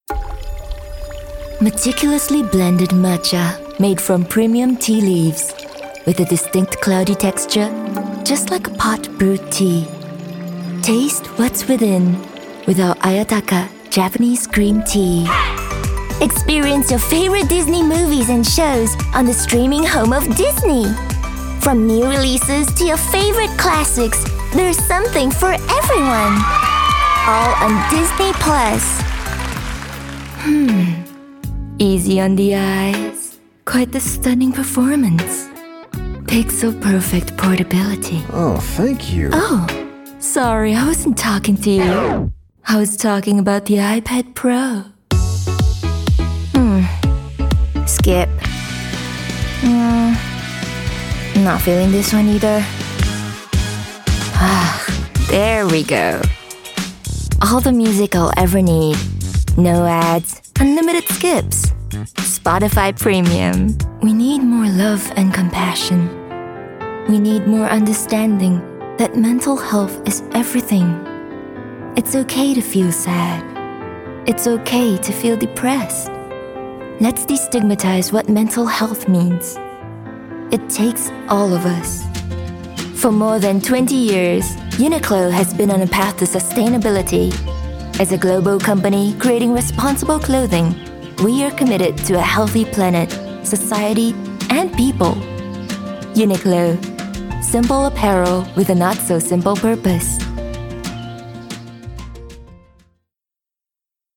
Anglais (Singapour)
Warm Trustworthy Genuine